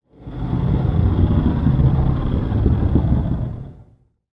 Жуткий скрип гроба по полу